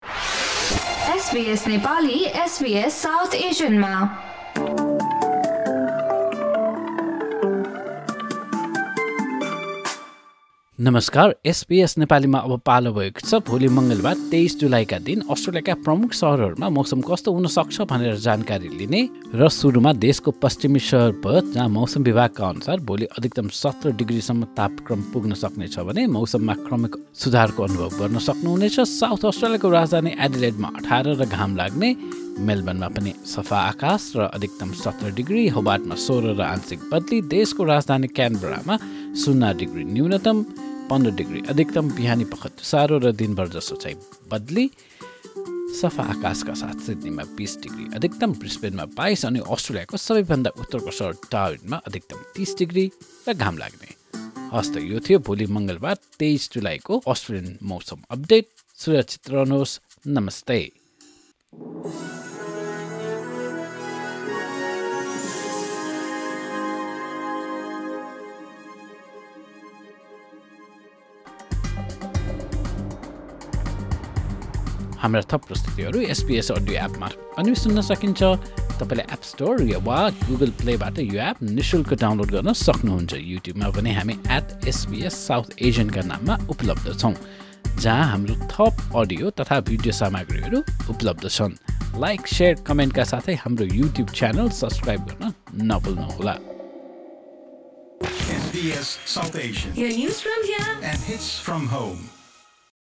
A quick Australian weather update for Tuesday, 23 July 2024, in Nepali language.